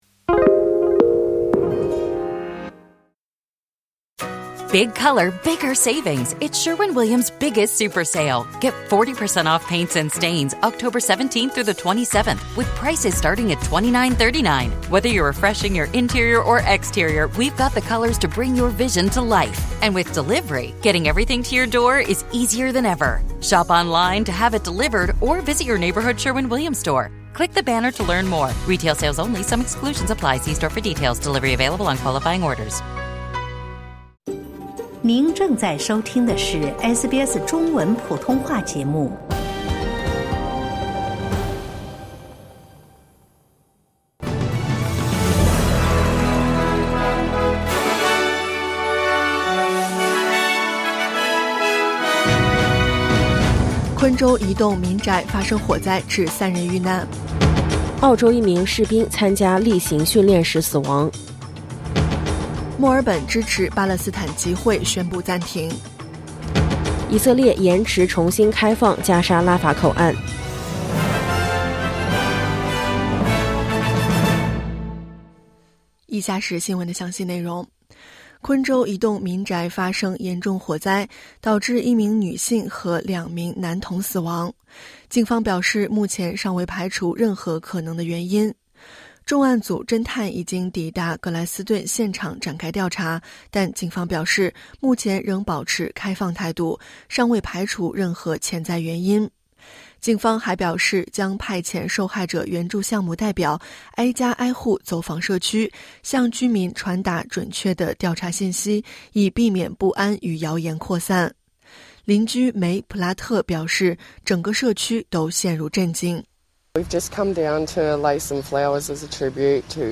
SBS早新闻（2025年10月18日）